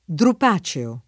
drupaceo [ drup #© eo ]